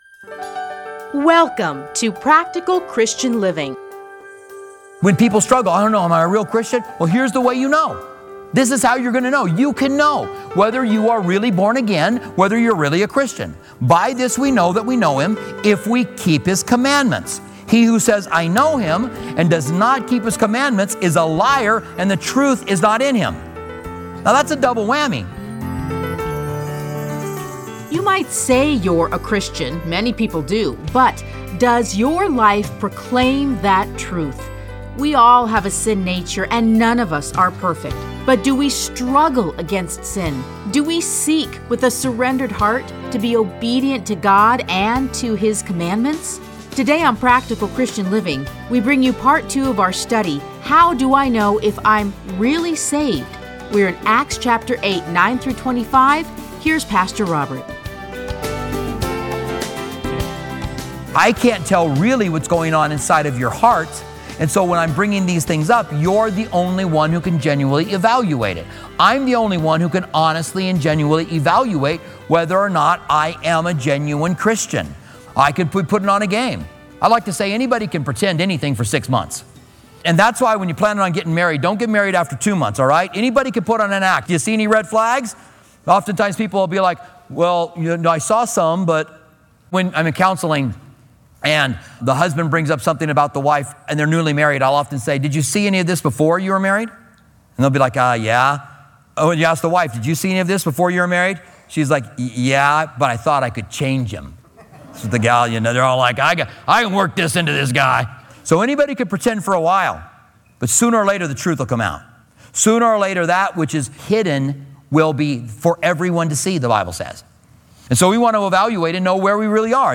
Listen to a teaching from Acts 8:9-25.